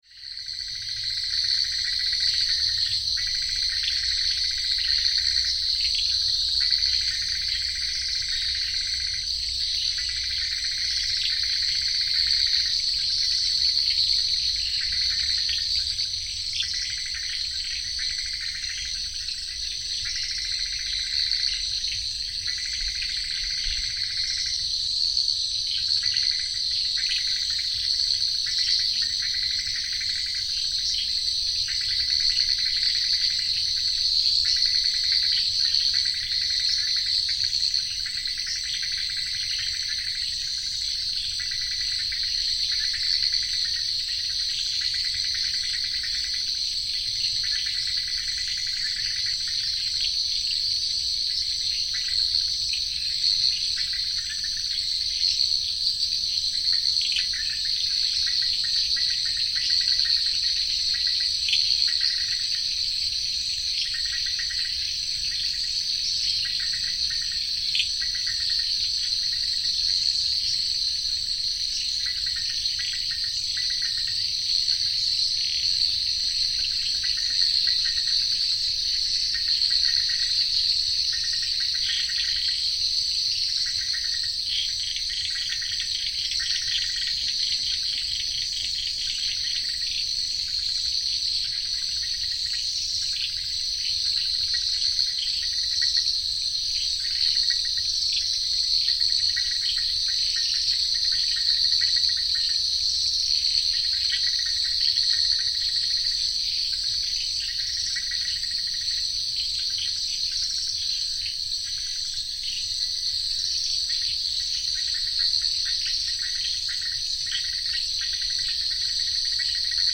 Tonal and polyrhythmic amphibians in a wetland
Pantuase is a new wetland location added to two other wetland locations namely Trom and Bonya which were previously recorded in 2021 and 2022. Those tonal frogs heard in the recording have never been acoustically captured so i was amazed by these unique sounds they were making when the recordings were sent to me.